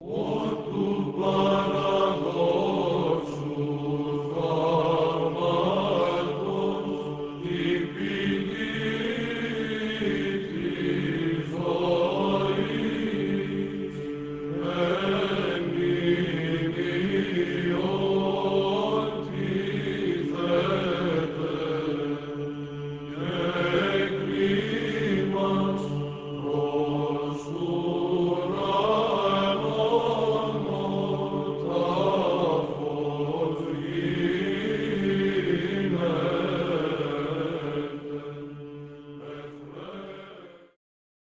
was performed on June 1998 by the University Byzantine Choir
2. Prosomion of the Dormition, First mode